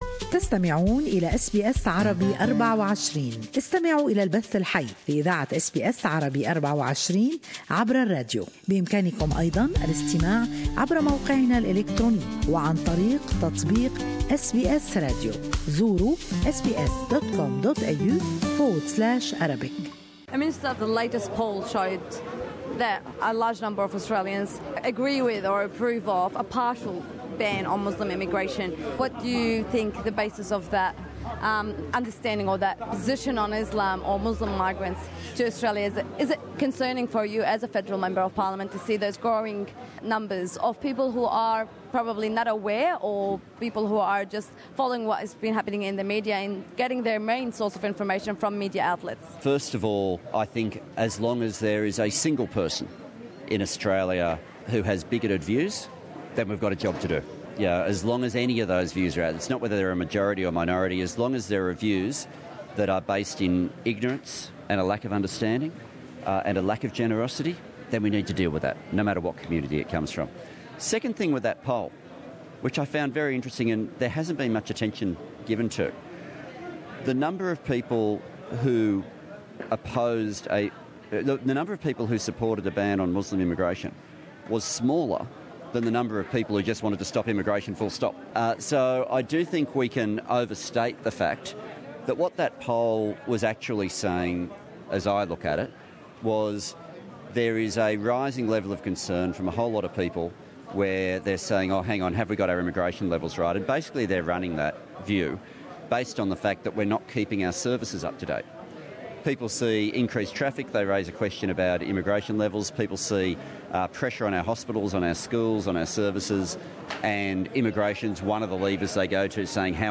hadow Minister for Citizenship Tony Burke speaking to SBS Arabic24 Source: SBS Arabic24